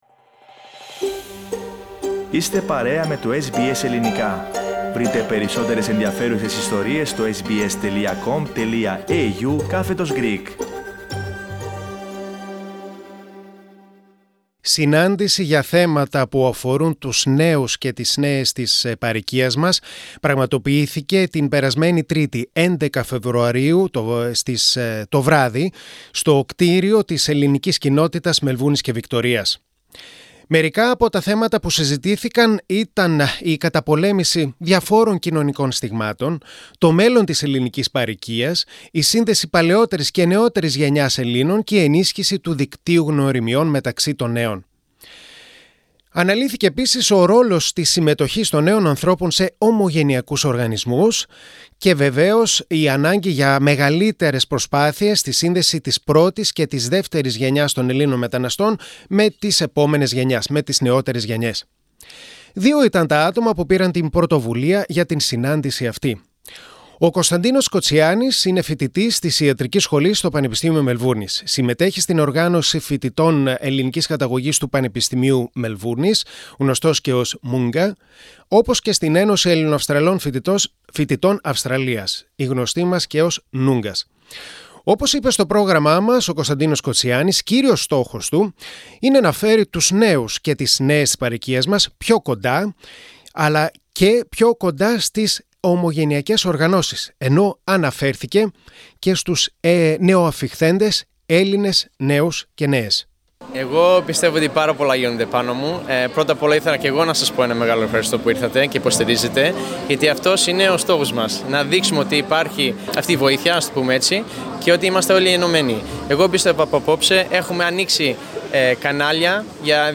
Συνάντηση για θέματα που αφορούν την νεολαία της παροικίας μας, διοργανώθηκε την Τρίτη 11 Φεβρουαρίου το βράδυ στην Ελληνική Κοινότητα Μελβούρνης. Το SBS Greek βρέθηκε εκεί και συγκέντρωσε απόψεις από νέους και νέες.
Greek Youth Summit February 2020 Source: SBS Greek